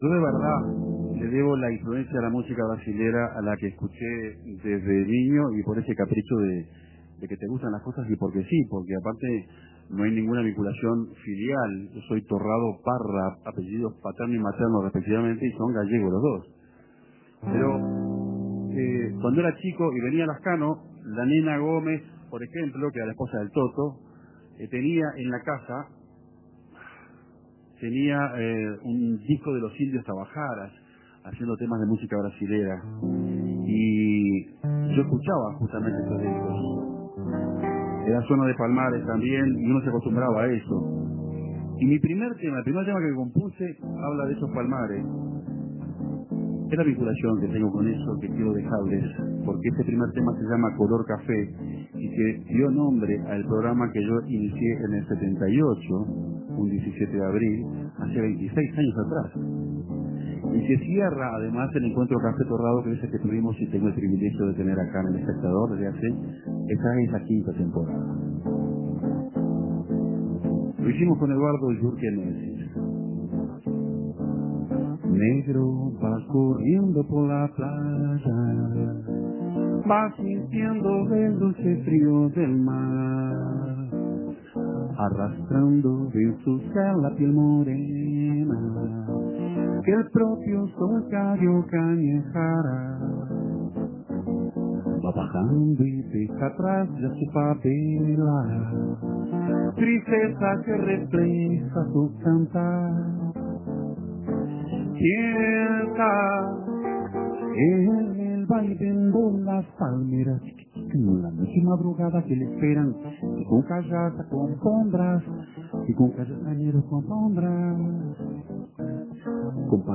cantó para el publicó que acompañó el programa en el Parador Rocha.